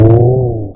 mobs_cow.ogg